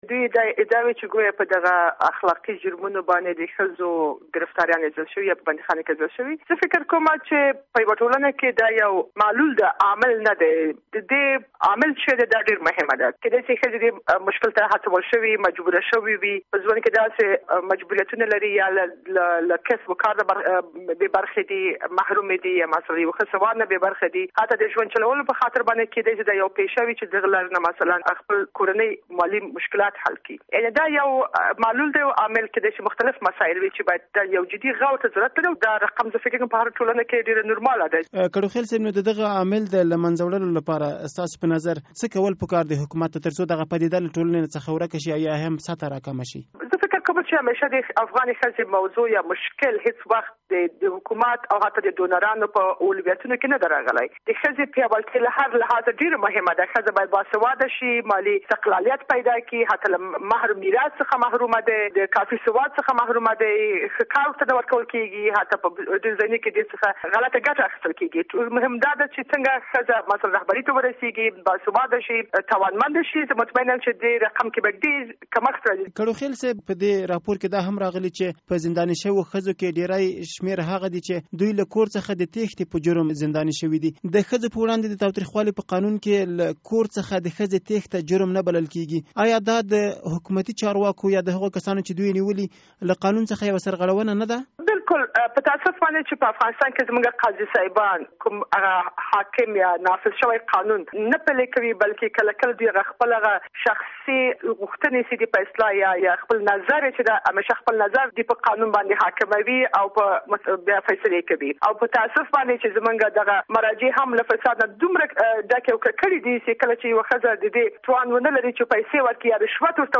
له شینکی کړوخیل سره مرکه